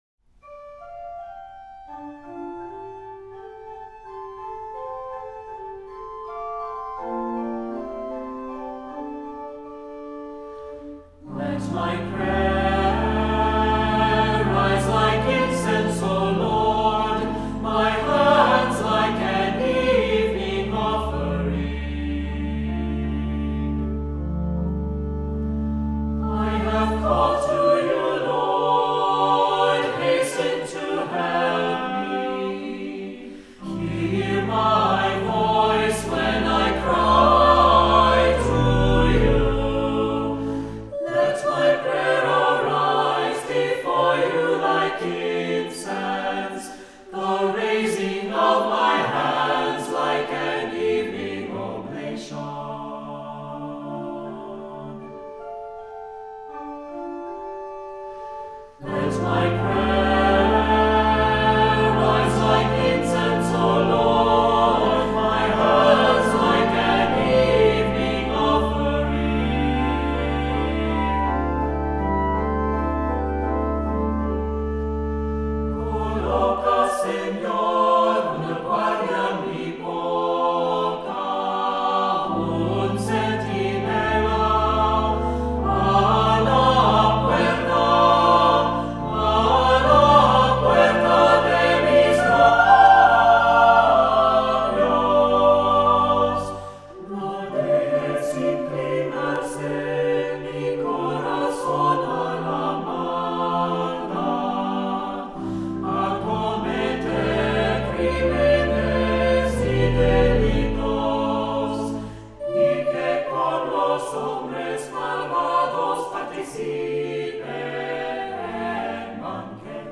Voicing: Assembly,SATB,Cantor